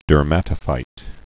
(dûr-mătə-fīt, dûrmə-tə-)